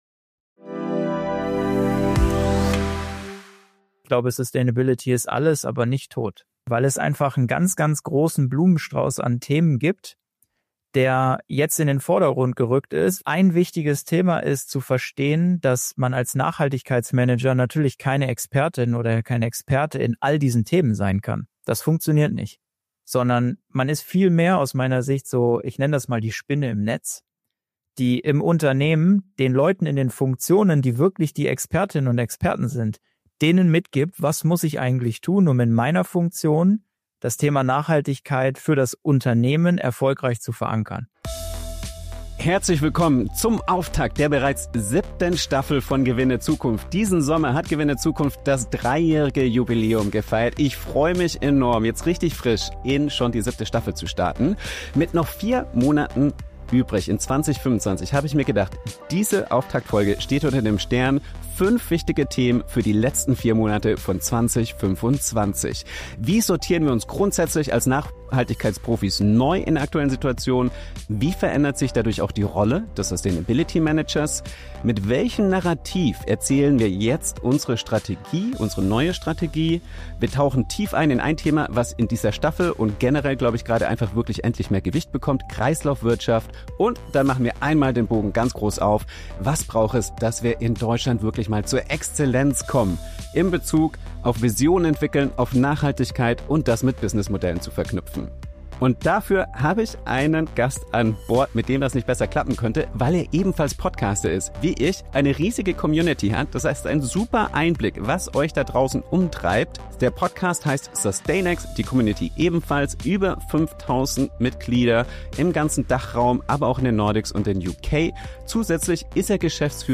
Gewinne Zukunft ist der Podcast für Leader und Professionals, die in ihrem Unternehmen Nachhaltigkeit wegweisend umsetzen wollen. Alle zwei Wochen interviewe ich absolute Pioniere und Expert*innen aus allen Branchen, die Nachhaltigkeit bereits unternehmerisch umgesetzt haben.